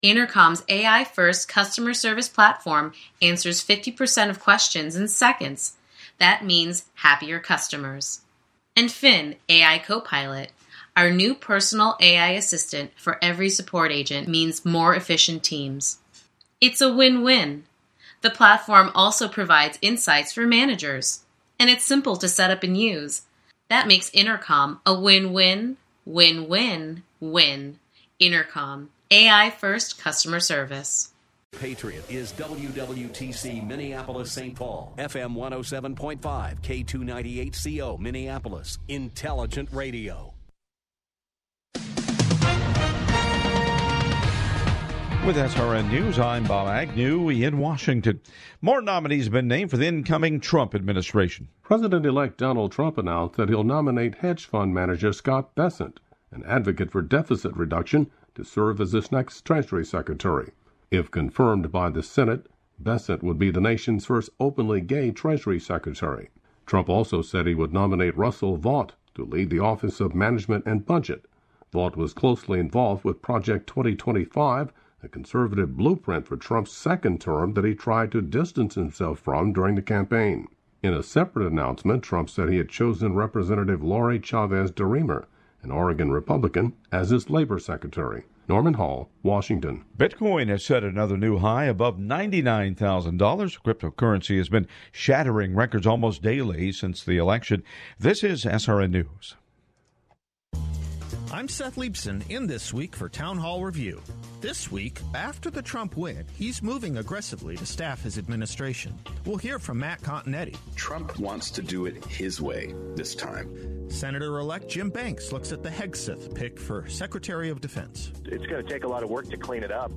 This inspiring conversation will leave you with insightful ways to make positive changes every day.